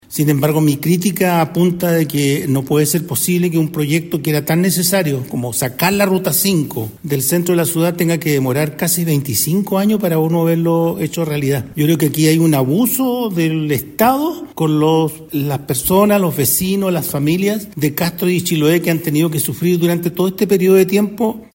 Similar parecer compartió el exjefe comunal Nelson Águila, ya que indicó que no es posible que un proyecto tan necesario, que promete dar solución a la congestión vehicular en el centro de Chiloé, tenga que demorar más de 25 años.